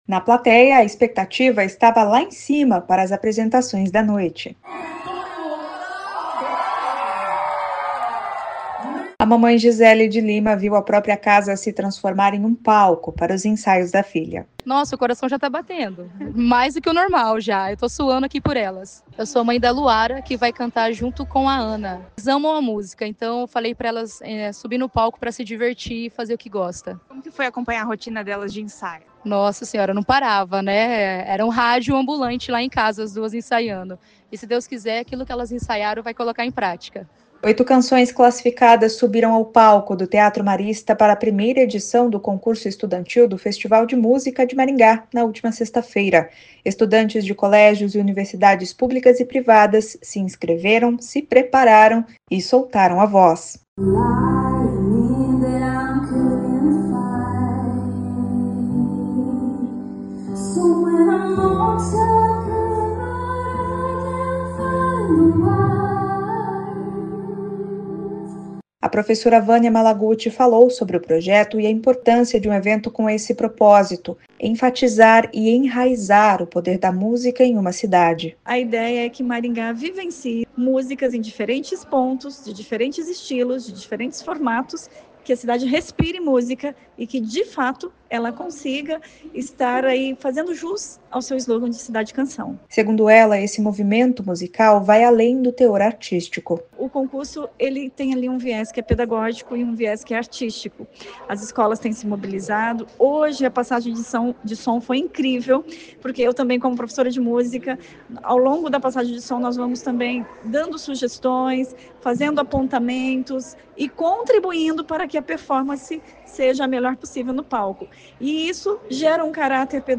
Oito canções classificadas tomaram o palco do Teatro Marista na primeira edição do Concurso Estudantil do Festival de Música de Maringá 2025, nesta sexta-feira (17).